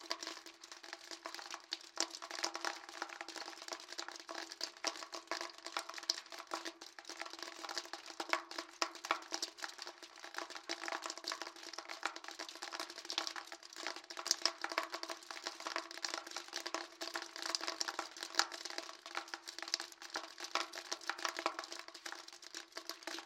水流入下水道和滴漏
描述：最后一点水在浴缸排水管里潺潺流淌，然后滴了几滴水。水也从排水管滴到下面的水阱中。
Tag: 汩汩 排水管 浴缸 浴缸 水滴 流动 漏极